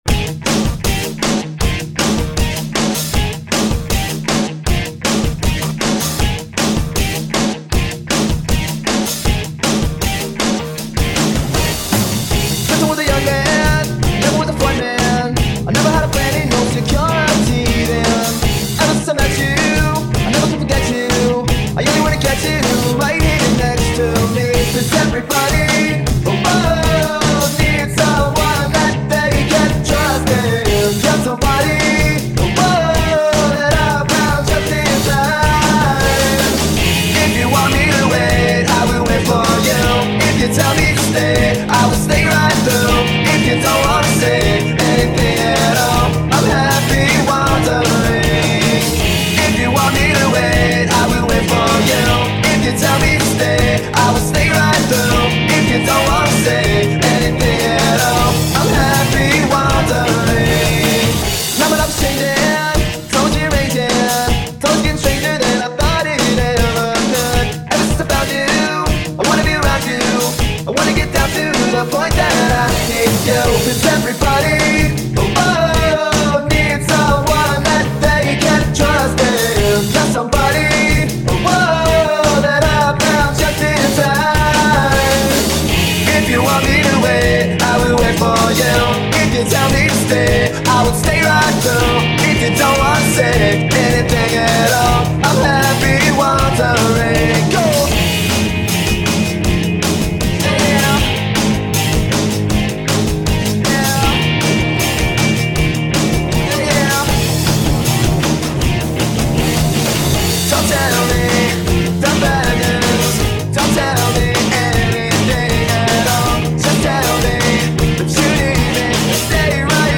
music guitar rip
The source track has been mixed with the vocals